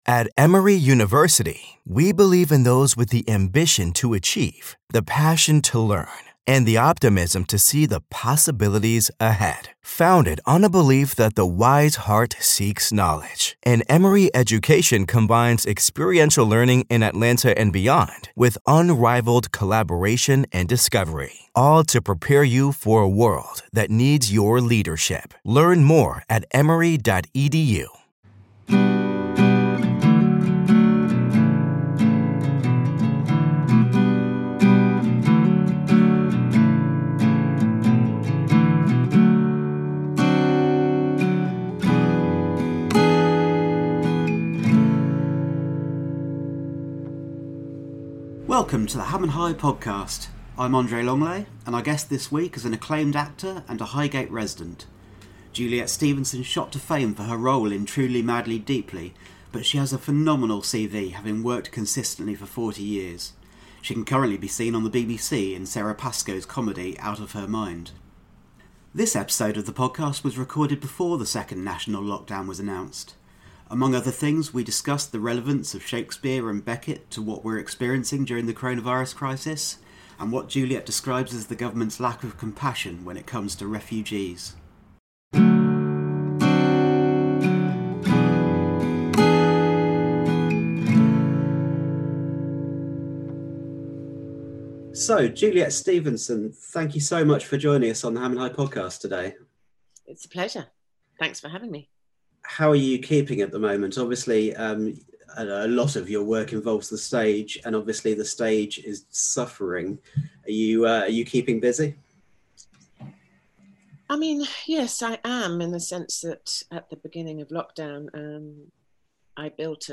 This episode of the podcast was recorded before the second national lockdown was announced. Among other things, we discuss the relevance of Shakespeare and Beckett to what we are experiencing during the coronavirus crisis, and what Juliet describes as the government’s "lack of compassion" when it comes to refugees.